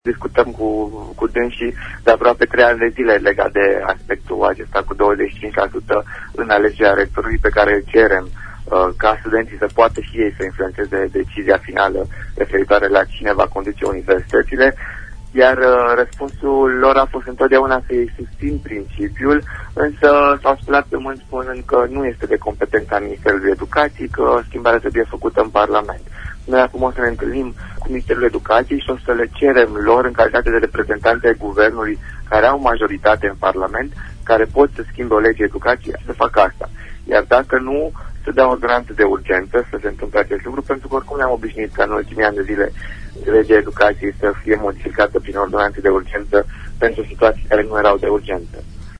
invitat la “Pulsul zilei”: